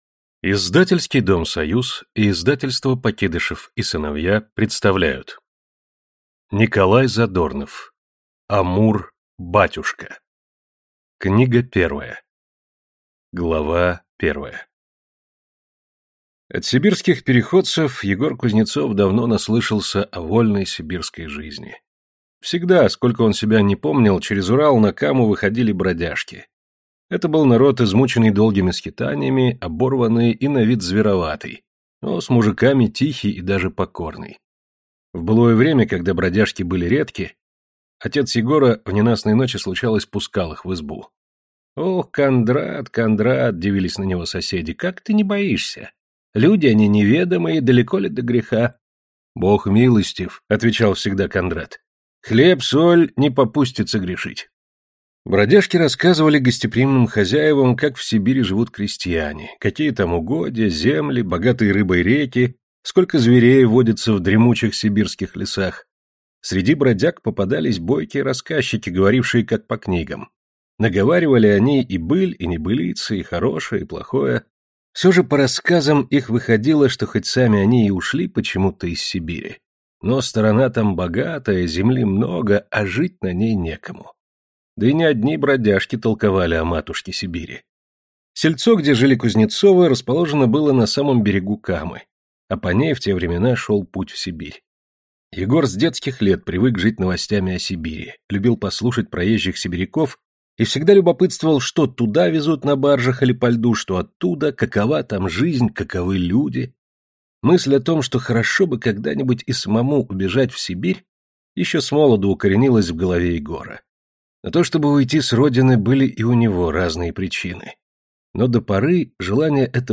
Аудиокнига Амур-батюшка | Библиотека аудиокниг